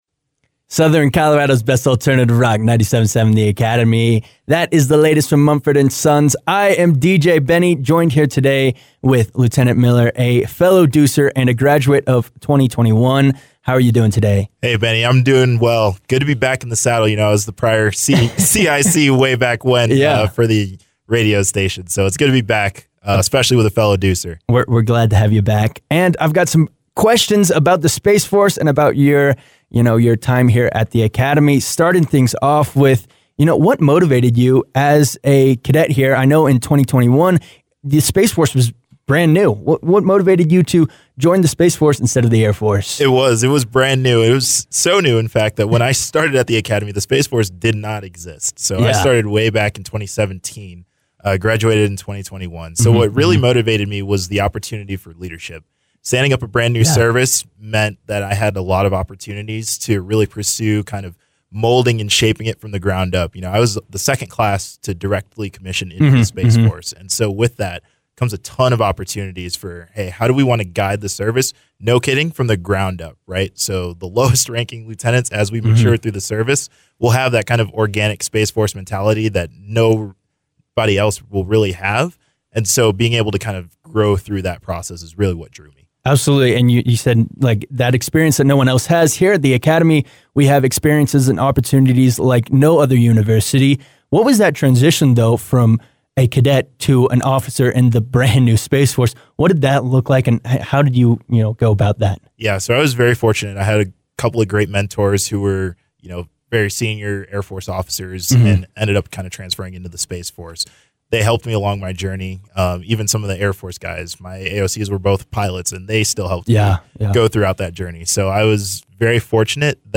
KAFA Cadet Interview